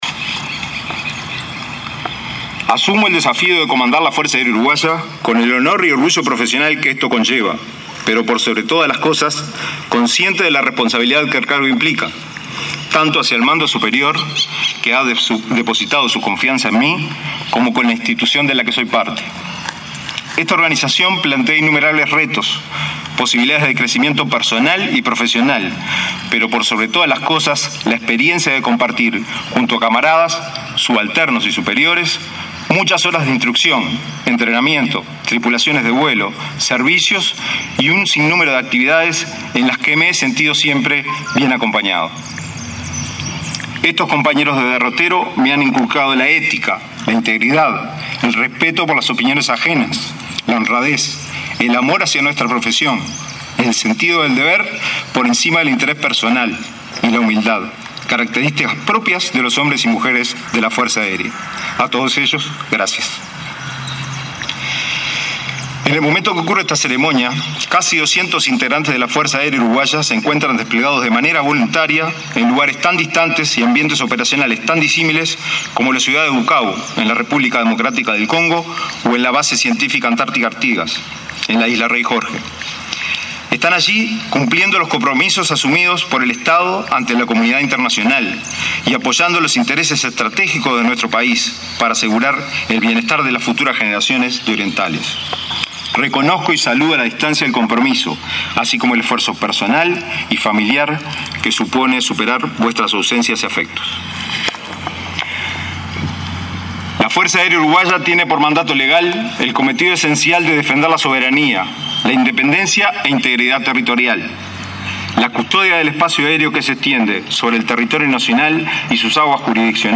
Con la presencia del presidente Tabaré Vázquez y el ministro de Defensa, Jorge Menéndez, se realizó este viernes el acto de asunción del nuevo comandante en jefe de la Fuerza Aérea Uruguaya, Hugo Marenco. En su alocución, el jerarca recordó el trabajo del personal en lugares lejanos, como la Antártida, y el trabajo coordinado con los países vecinos en la zona fronteriza.